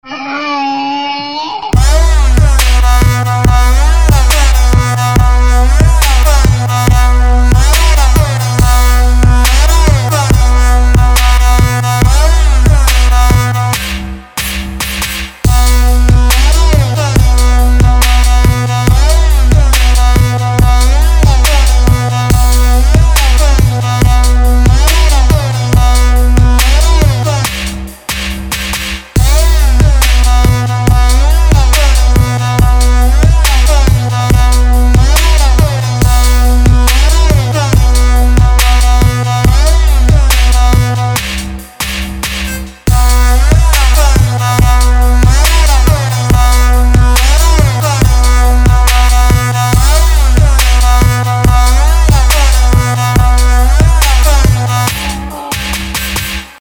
• Качество: 320, Stereo
Trap
крик осла
осла и мощный трэп!